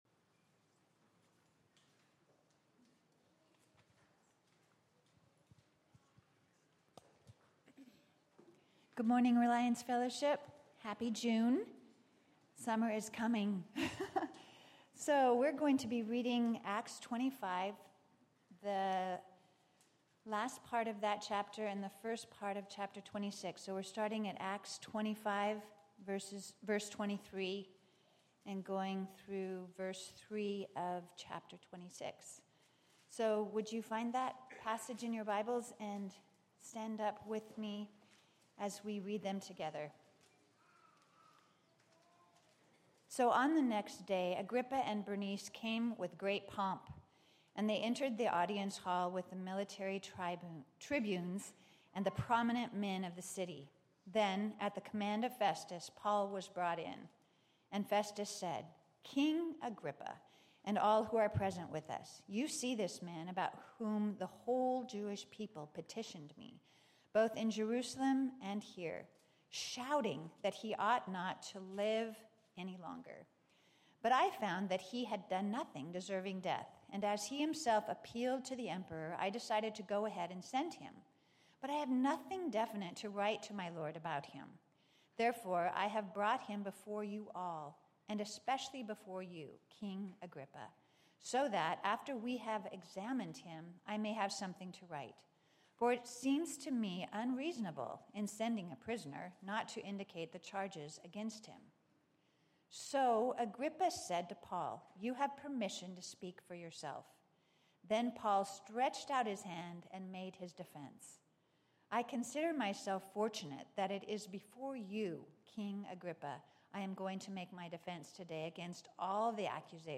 Preaching – Acts 26